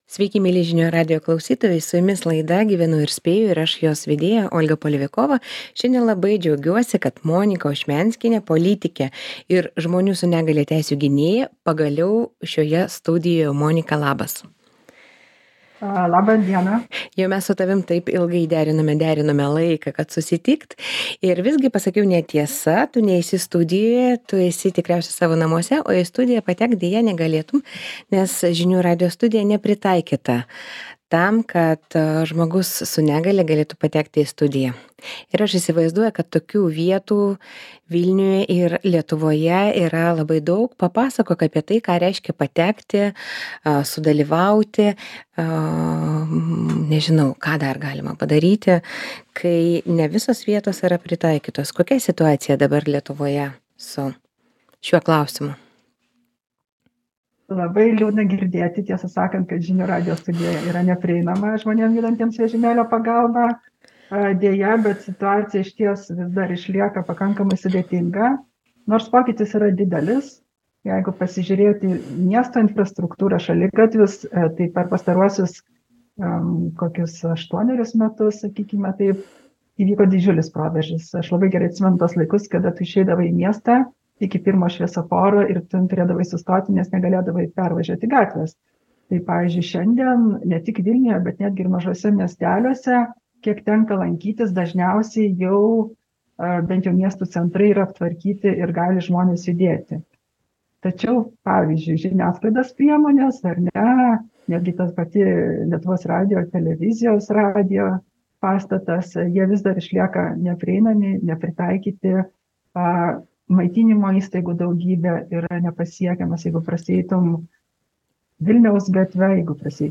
Kalbame su Monika Ošmianskiene, politike ir aktyvia žmonių su negalia teisių gynėja. Ką reiškia matyti miestą ir gyvenimo galimybes žmogaus, turinčio negalią, akimis?